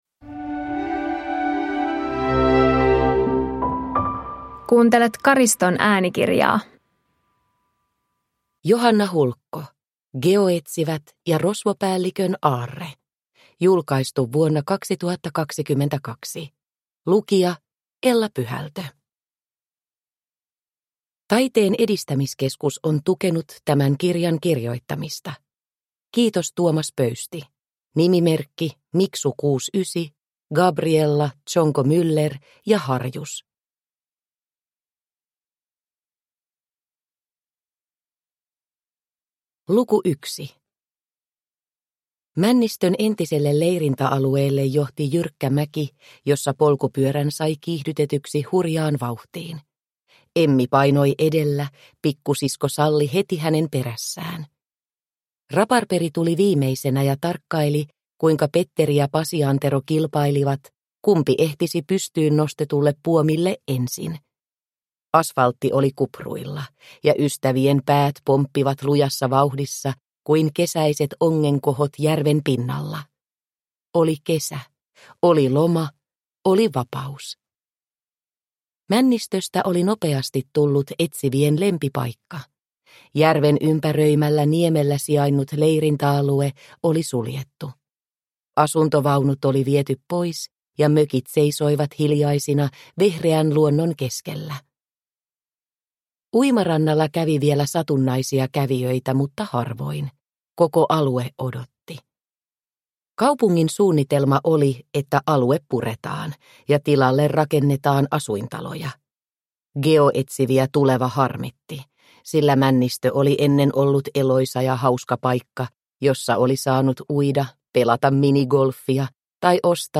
Geoetsivät ja rosvopäällikön aarre – Ljudbok – Laddas ner